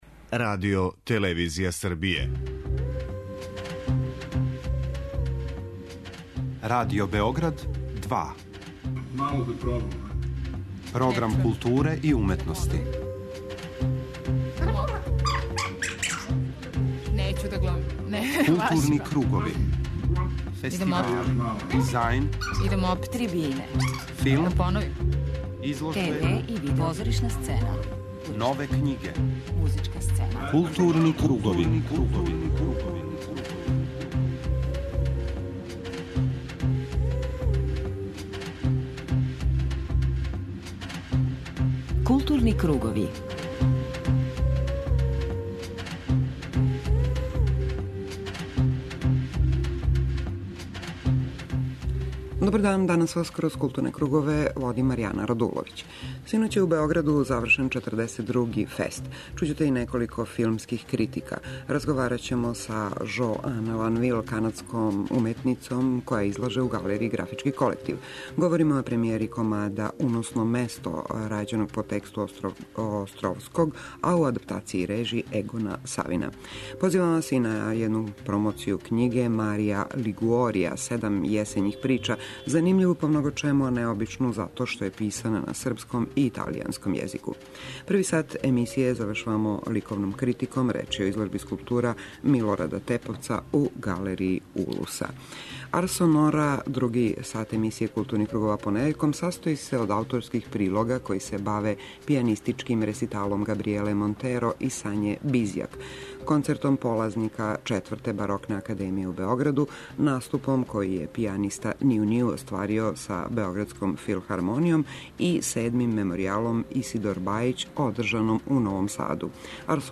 преузми : 53.90 MB Културни кругови Autor: Група аутора Централна културно-уметничка емисија Радио Београда 2.